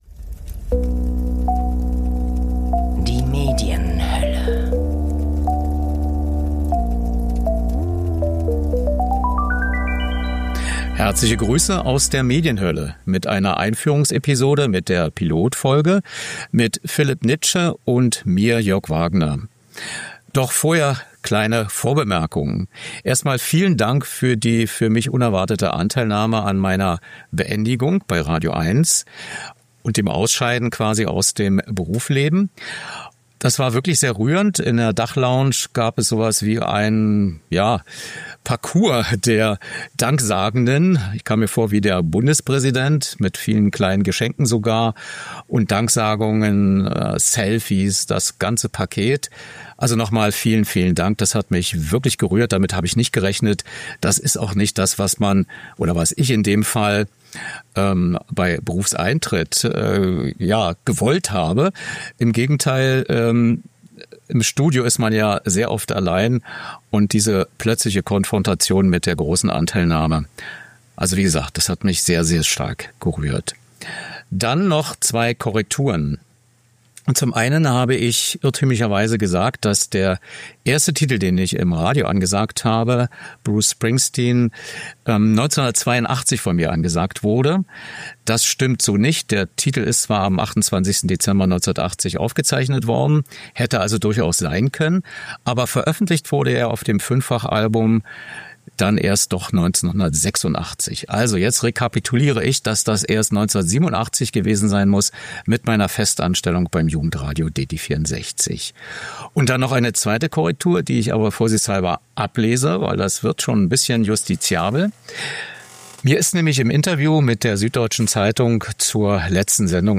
Neujahrsgespräch beim Italiener - Einführung